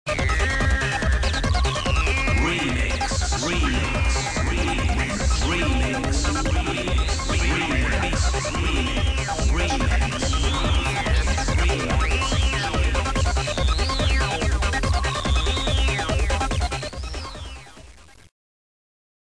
GOA Classic